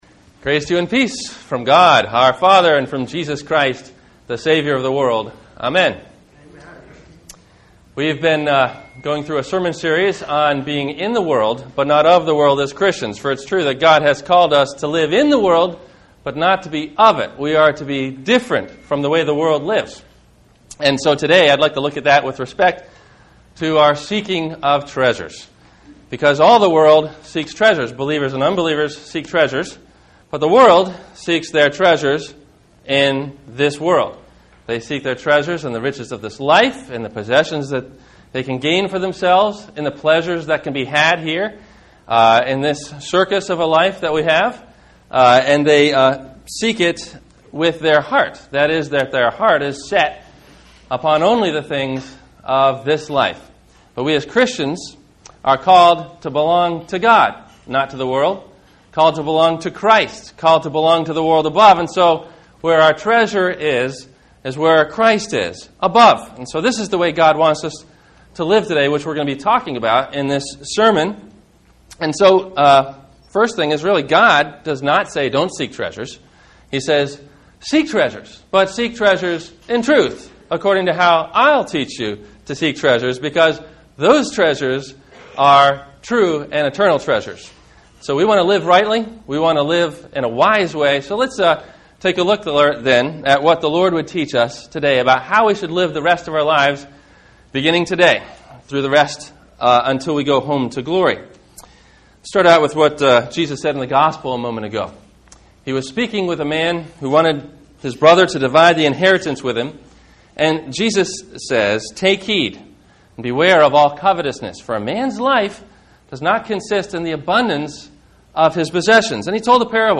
Treasure Above – Sermon – September 27 2009